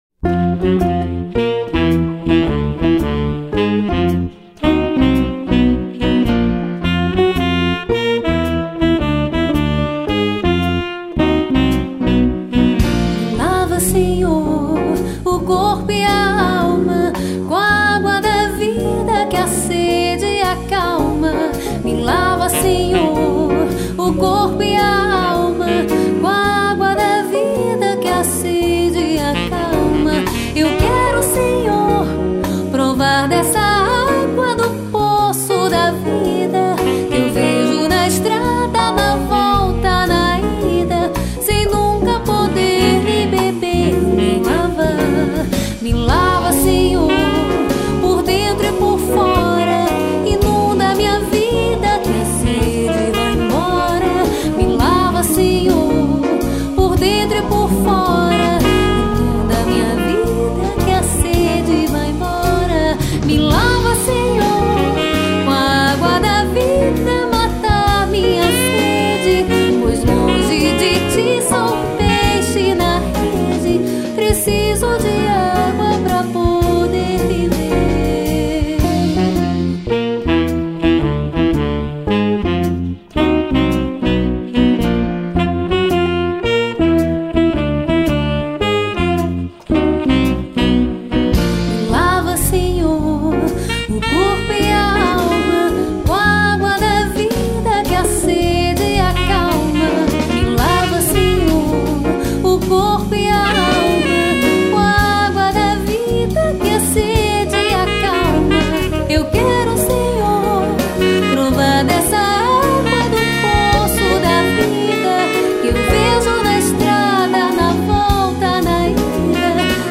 207   03:04:00   Faixa:     Canção Religiosa